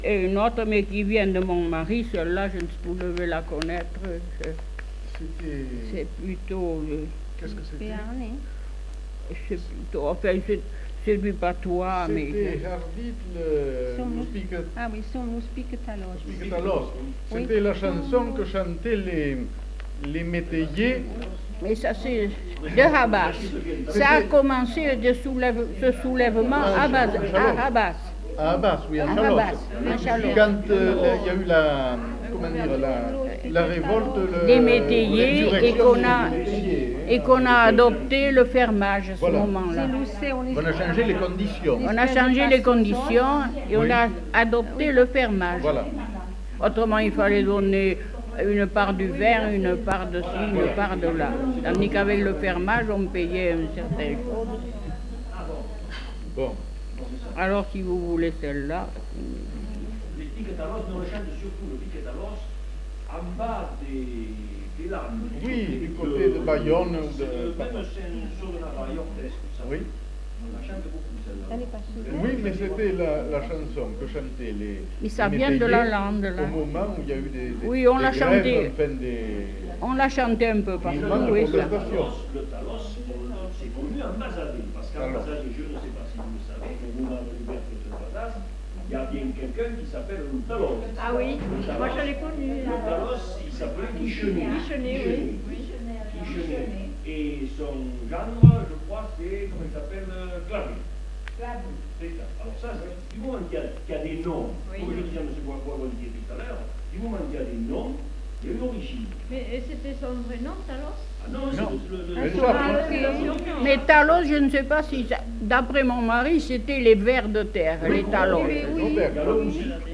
Aire culturelle : Bazadais
Lieu : Bazas
Genre : chant
Effectif : 1
Type de voix : voix de femme
Production du son : chanté
Ecouter-voir : archives sonores en ligne